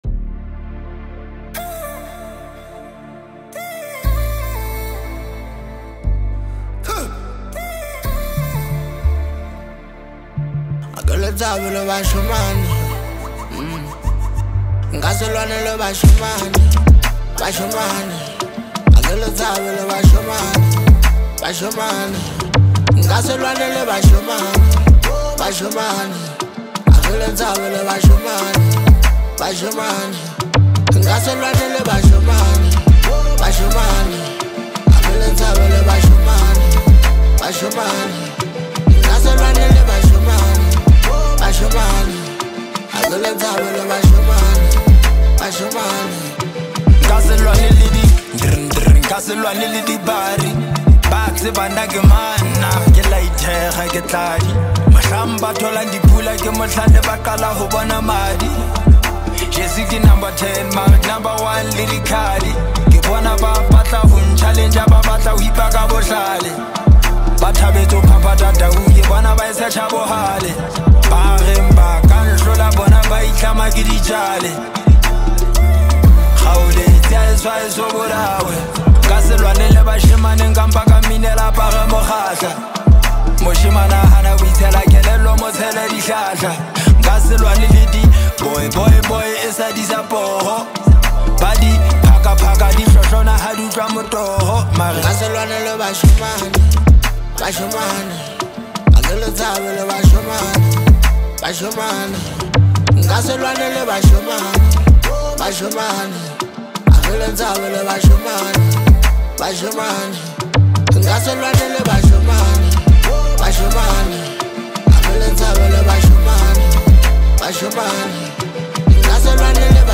” which is a collection of seven incredible Hip Hop tracks.
It has got everything from catchy beats to heartfelt lyrics.